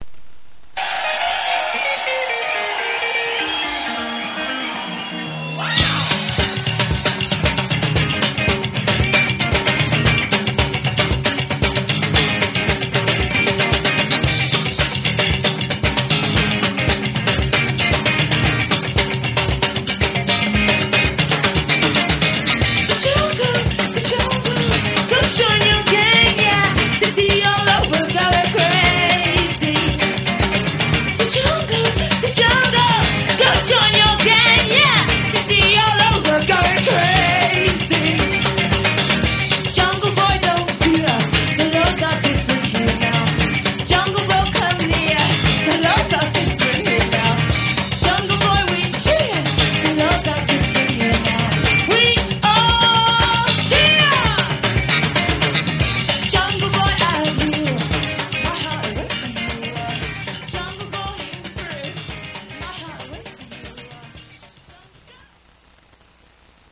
270Kb Live (Edit)